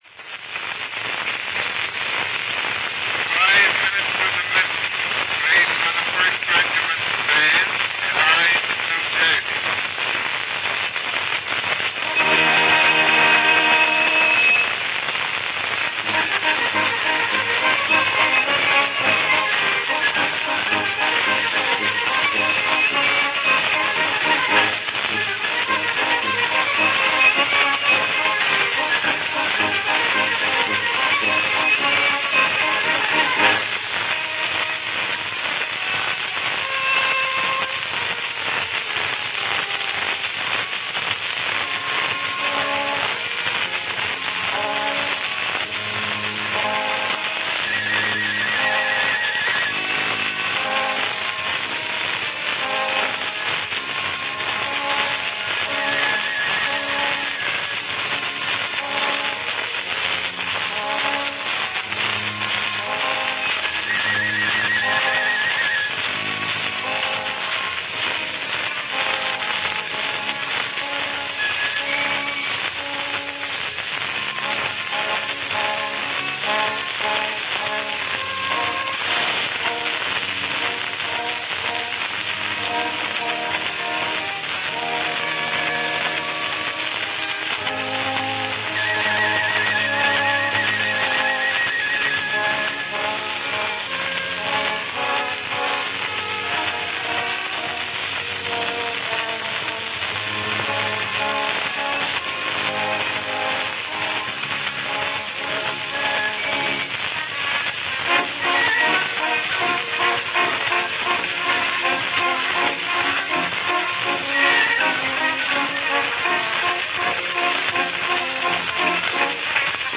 RealAudio file from a wax cylinder recording